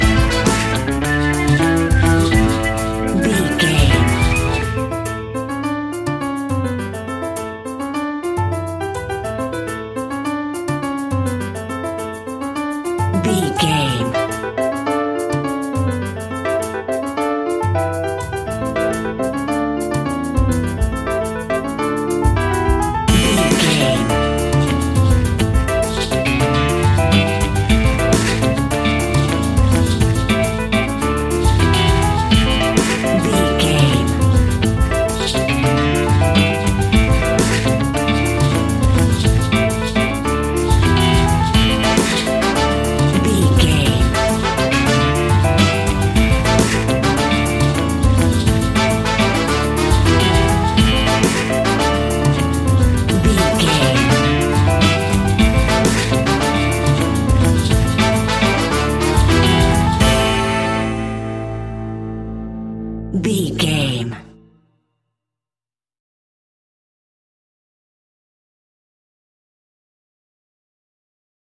Aeolian/Minor
instrumentals
maracas
percussion spanish guitar
latin guitar